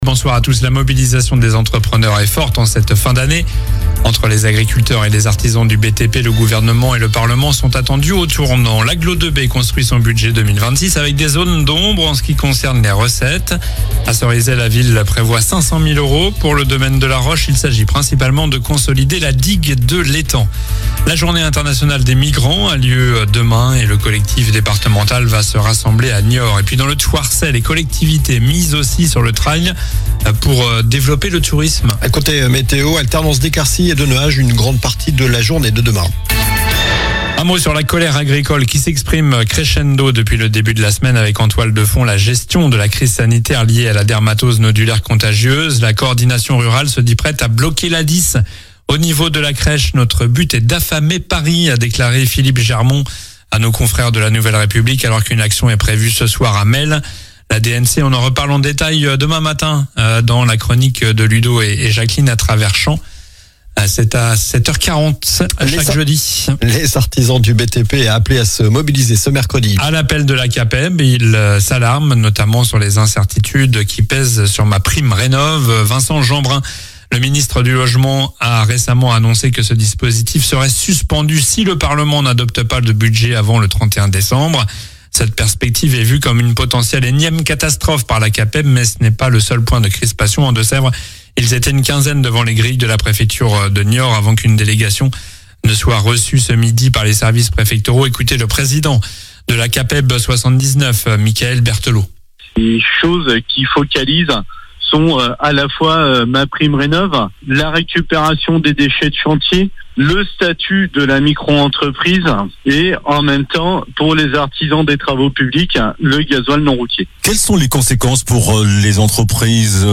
Journal du mercredi 17 décembre (soir)